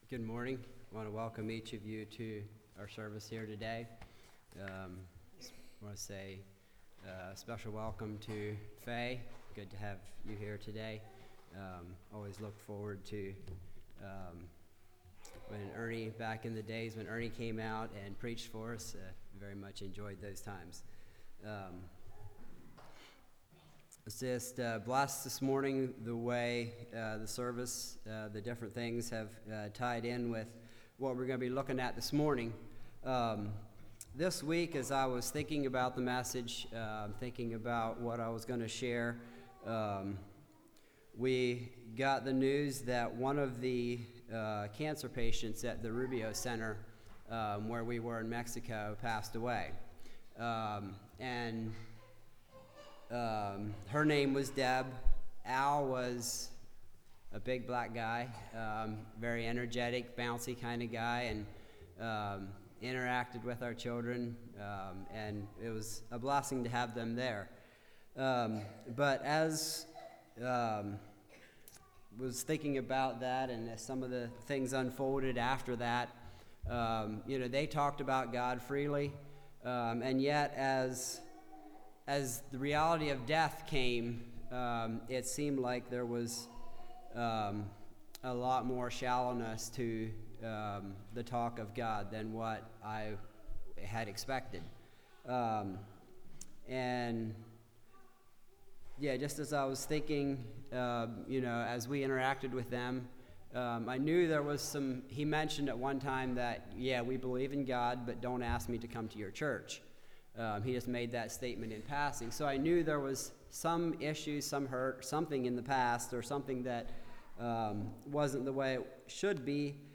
Passage: Mark 14:27-31 Service Type: Message Bible Text